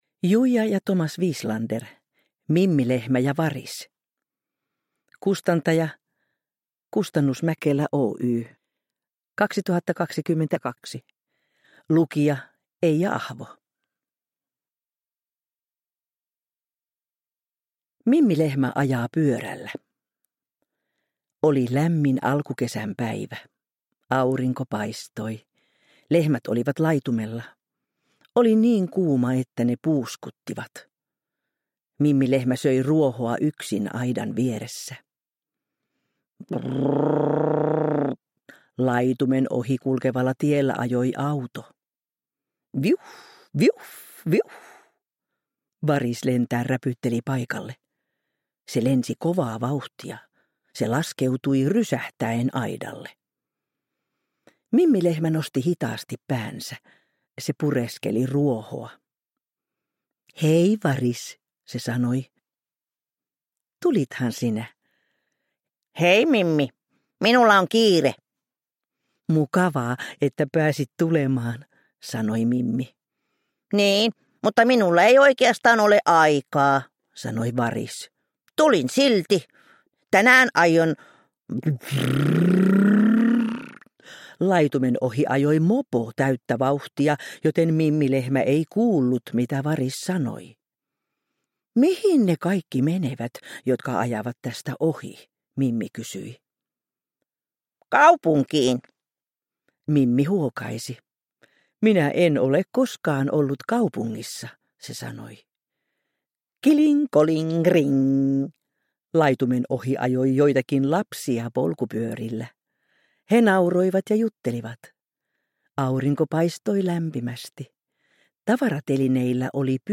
Mimmi Lehmä ja Varis – Ljudbok – Laddas ner